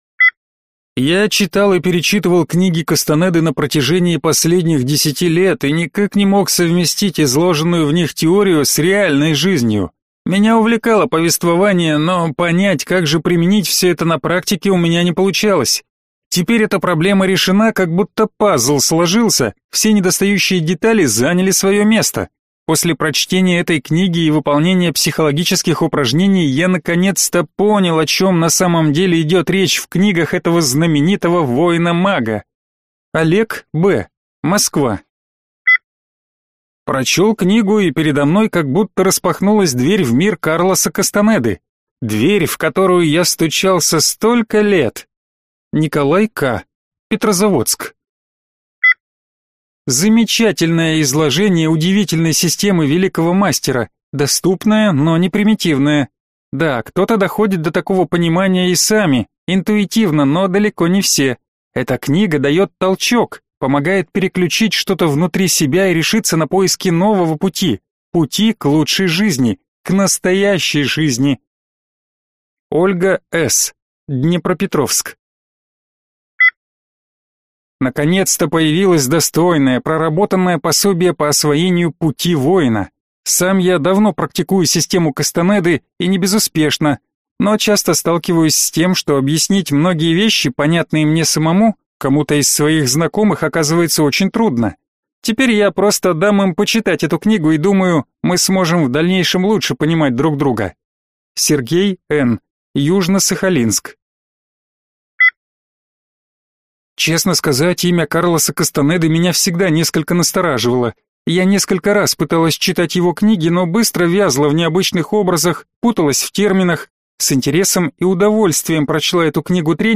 Аудиокнига Обрети силу Карлоса Кастанеды. 50 практик для развития сверxспособностей | Библиотека аудиокниг